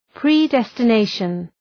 {prı,destə’neıʃən}